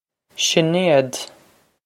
Sinéad Shih-nay-ud
Pronunciation for how to say
This is an approximate phonetic pronunciation of the phrase.